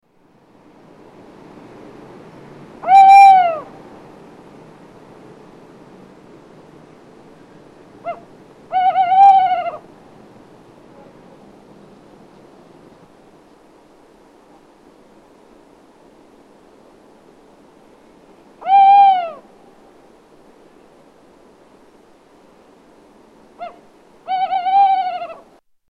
Tawny Owl
Category: Animals/Nature   Right: Personal
Tags: Science and Nature Wildlife sounds Bristish Animals British Wildlife sounds United Kingdom